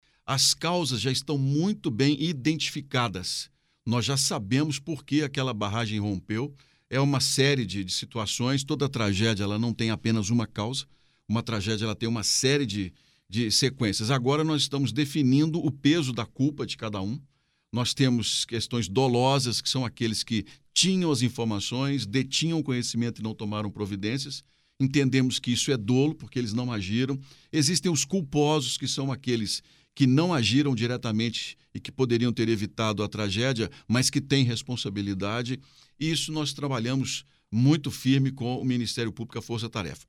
Em entrevista a nossa reportagem, o senador adiantou que as causas já foram identificadas.
senador Carlos Viana